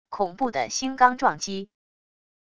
恐怖的星罡撞击wav音频